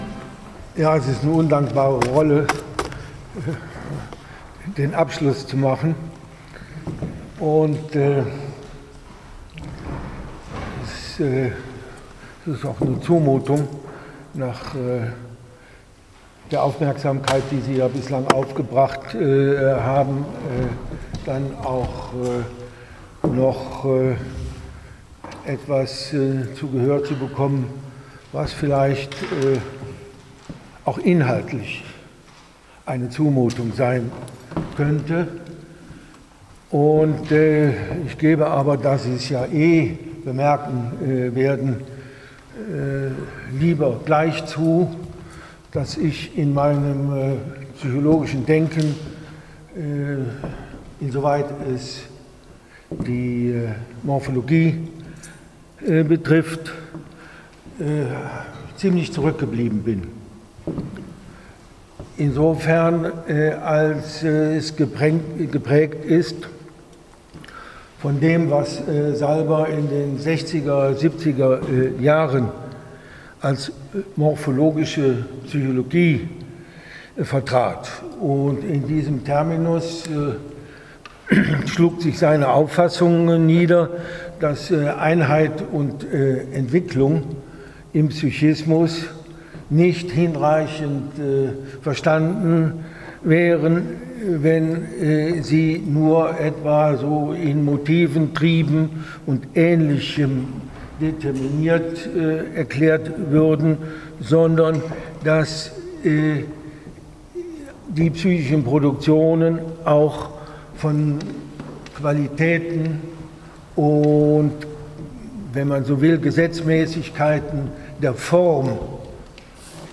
Sinn-Bilder-Sinn-Vortrag